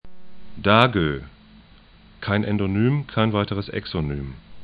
Dagden   'da:gø,  'dakdən Hiiumaa 'hi:juma: